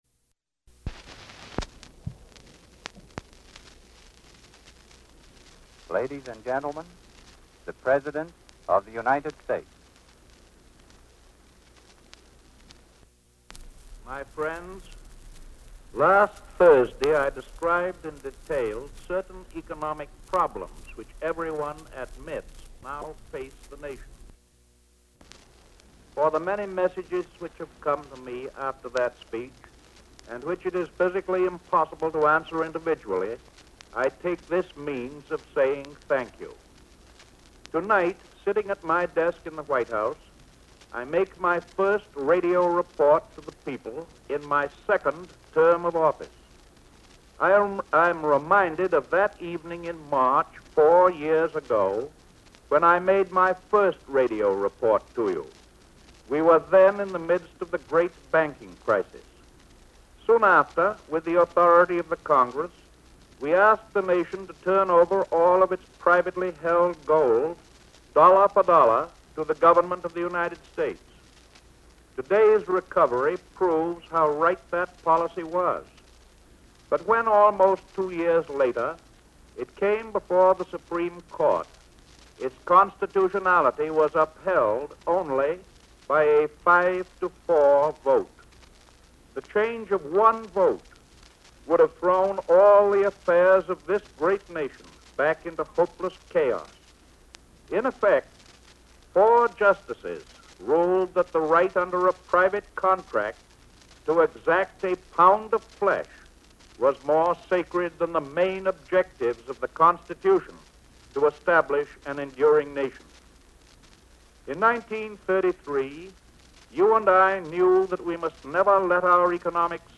Presidential Speeches | Franklin D. Roosevelt Presidency